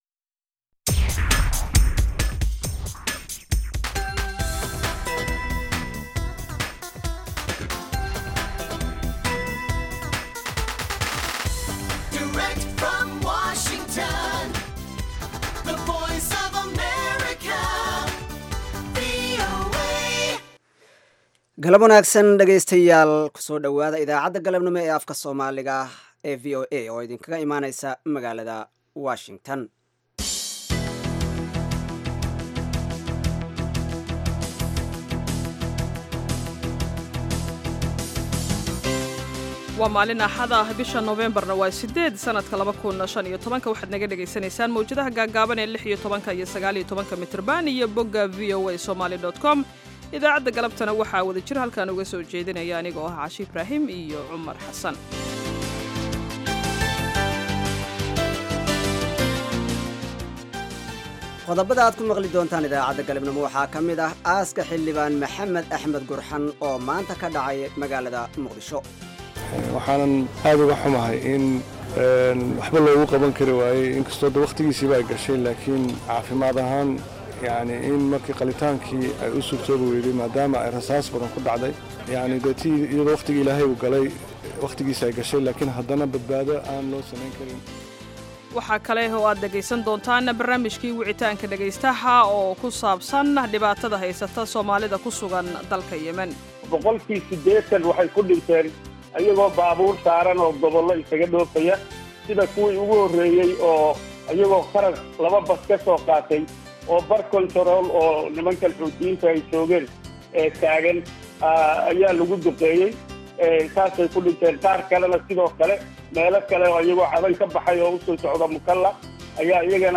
Idaacadda Galabnimo waxaad ku maqashaan wararka ugu danbeeya ee caalamka, barnaamijyo, ciyaaro, wareysiyo iyo waliba heeso.